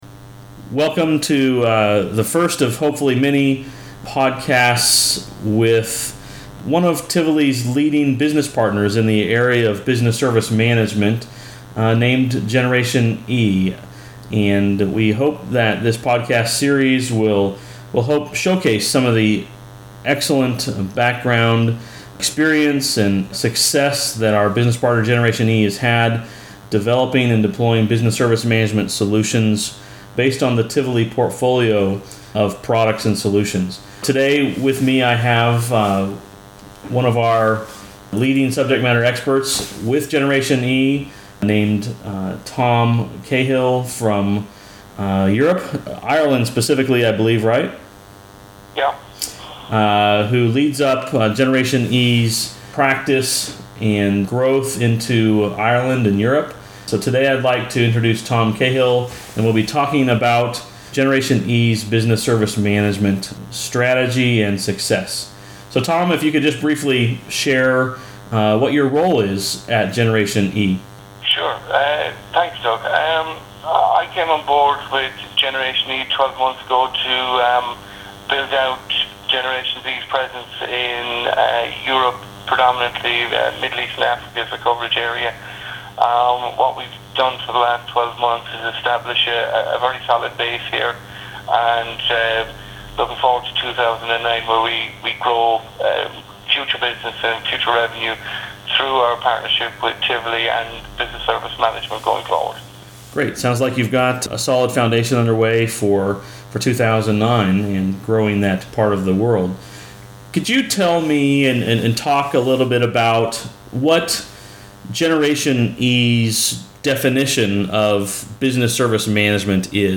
A Business Service Management Discussion